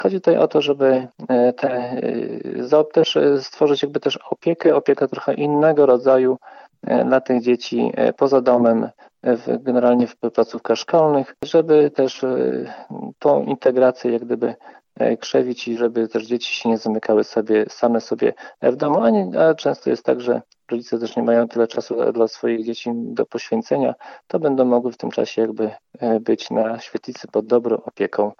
Jak mówi Zbigniew Mackiewicz, wójt gminy Suwałki, chodzi o stworzenie formy spędzania czasu poza domem, aby po lekcjach nie przebywały same.